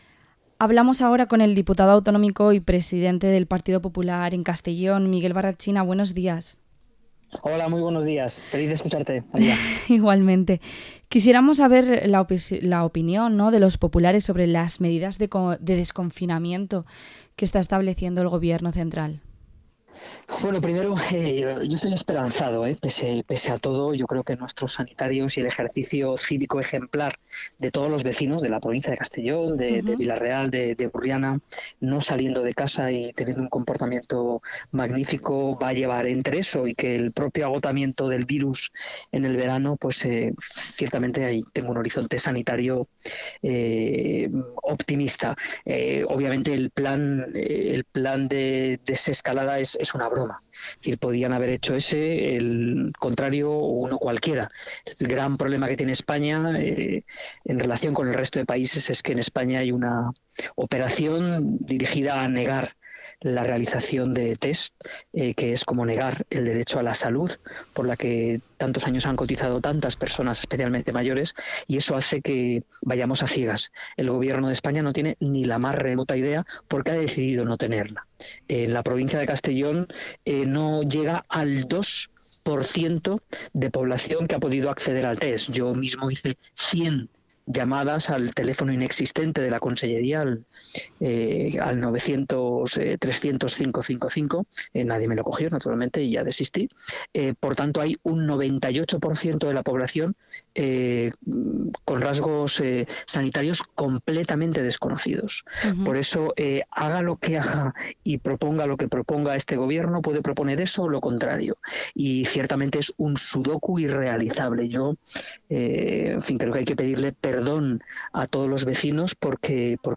Entrevista al diputado autonómico y Presidente del PP en Castellón, Miguel Barrachina